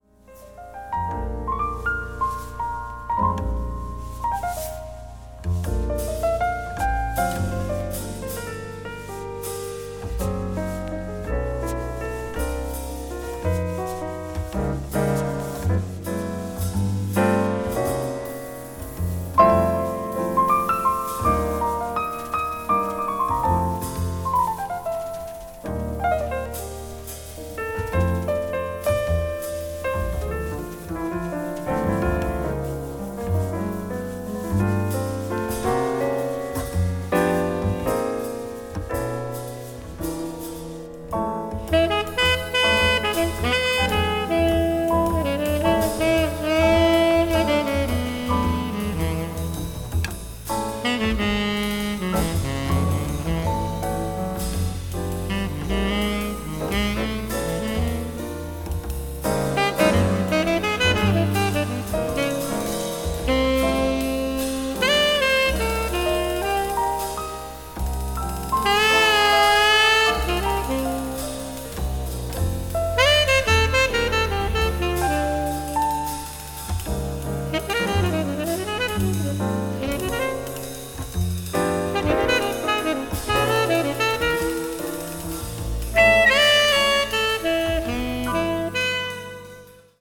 media : EX+/EX+(わずかにチリノイズが入る箇所あり)
なめらかに揺れるエレガントなアンサンブルが気持ち良いA2
contemporary jazz   ethnic jazz   free jazz   spritual jazz